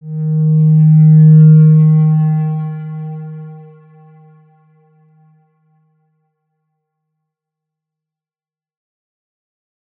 X_Windwistle-D#2-mf.wav